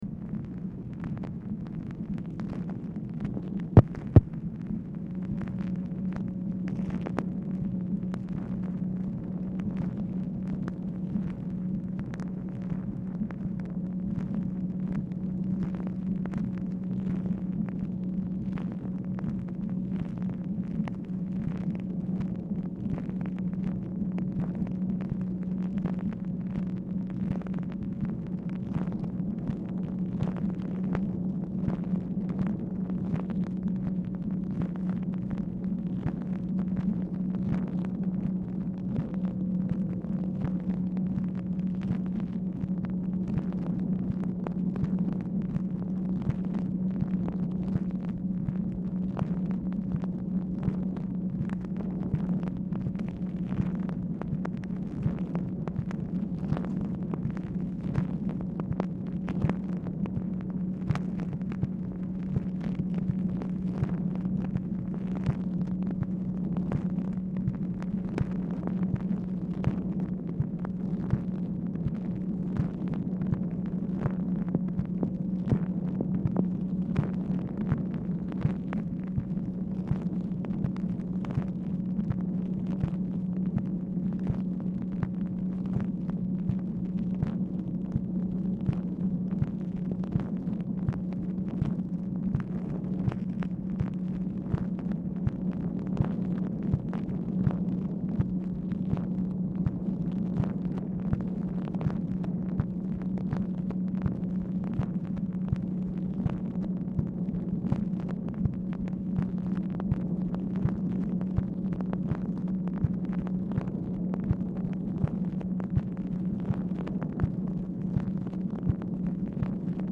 Telephone conversation # 13519, sound recording, MACHINE NOISE, 10/7/1968, time unknown | Discover LBJ